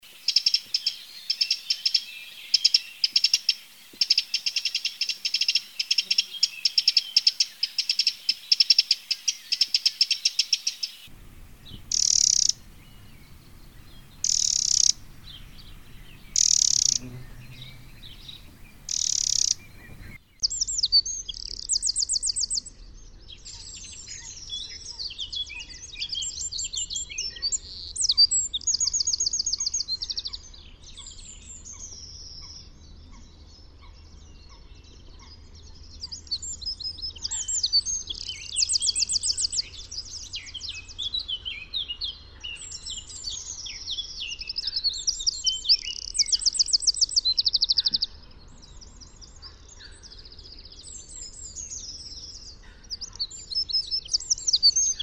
Troglodyte mignon
Le chant du troglodyte mignon est très perçant et puissant pour un oiseau aussi petit. Le chant est un mélange de trilles et de bavardages crépitants, finissant par un autre trille rapide et sec.
troglodyte.mp3